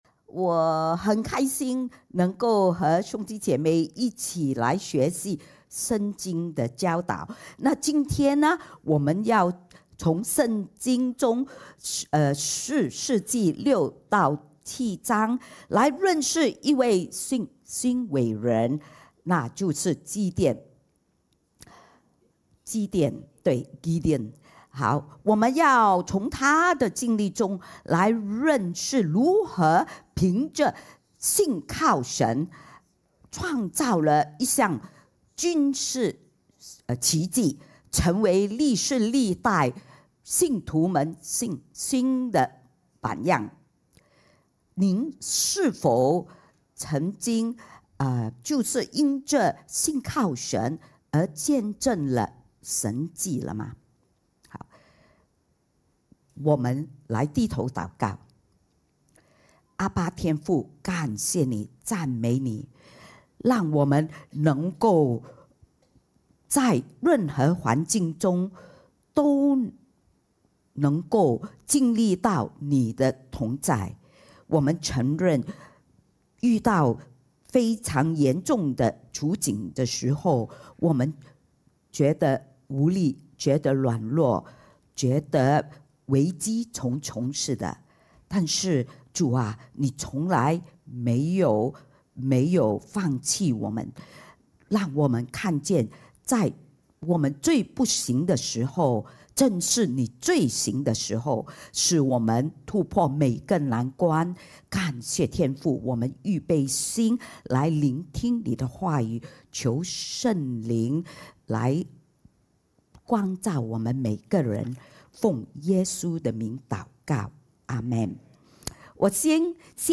Sermon Audio (.mp3)